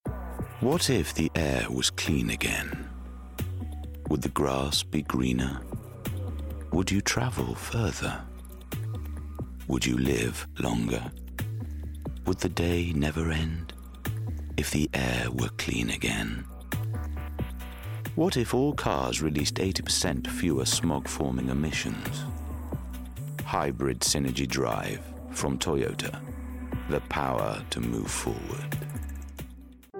Yorkshire
Male
Deep
Dry
Gravelly
TOYOTA COMMERCIAL